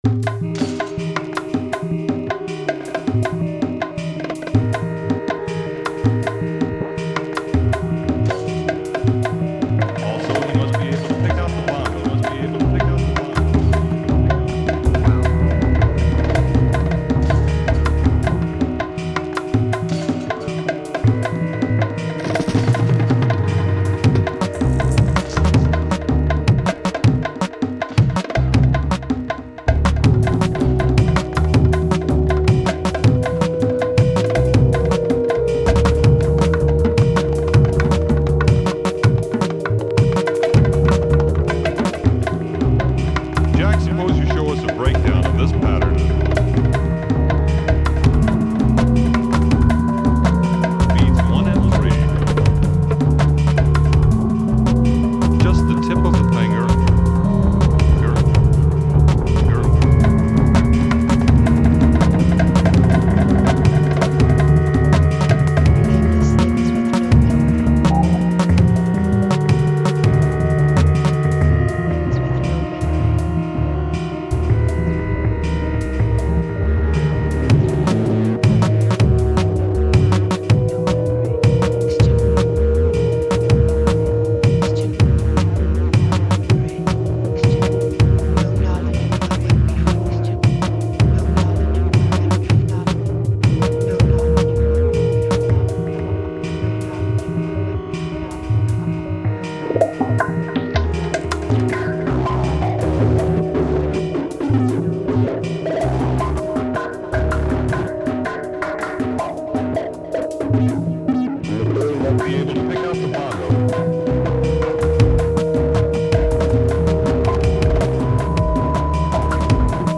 DnB Battle #1: WINNERS ANNOUNCED! plus feedback & rec notes